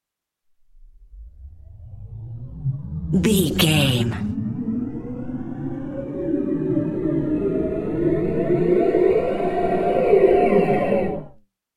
Powerup sci fi 240
Sound Effects
strange
high tech
sci fi